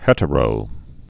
(hĕtə-rō)